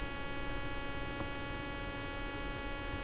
I’m trying to use a windows 10 laptop with a blue Yeti to record normal speech, and I’m using audacity version 2.2.2. Whenever I go to record on my laptop I get a weird buzz.
It’s a similar sound quality to the Mosquito whine, but it’s lower pitched, and it also happens if I’m using my laptop’s internal mic, and not just my usb mic.
Here’s a quick example of the buzz I’m getting.